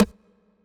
percussion.wav